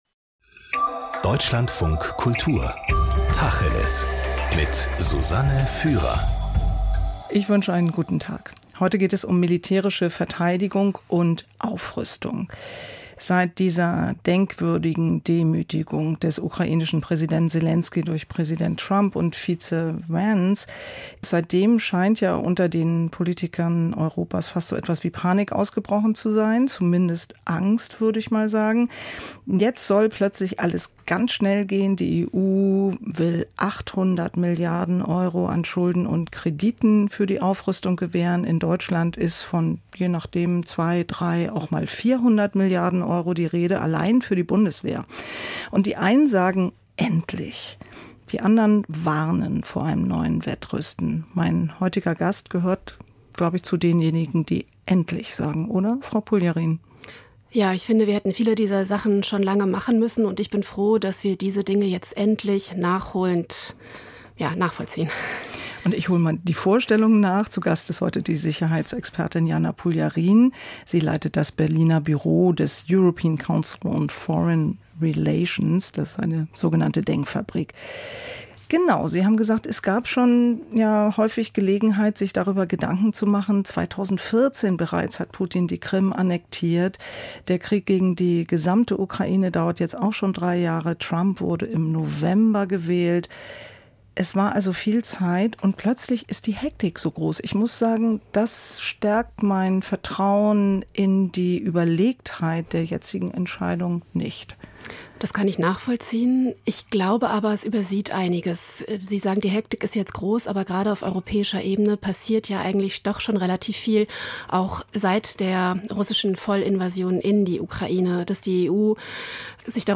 Gespräche - Tacheles
Es geht um Personen, Fakten, Meinungen. Wir reden mit einem Gast aus Politik oder Wirtschaft, aus Kultur oder Wissenschaft, aus Sport oder Gesellschaft über interessante und brisante Themen.